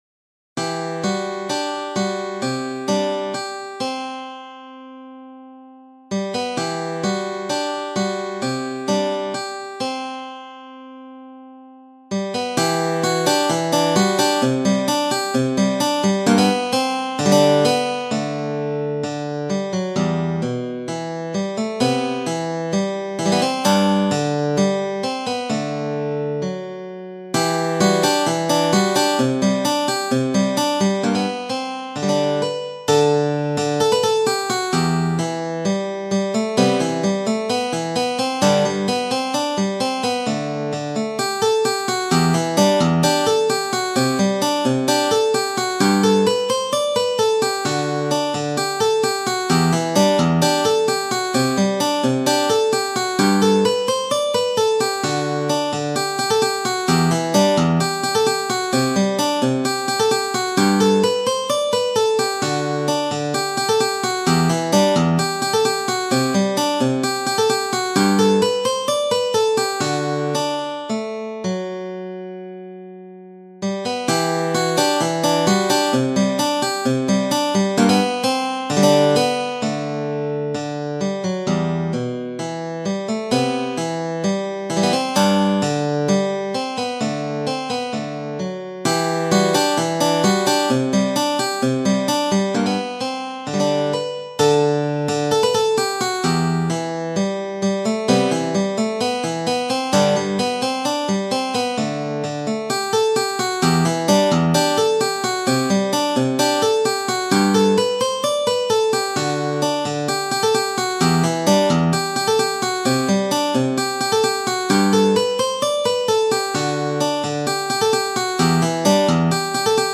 Ноты для гитары.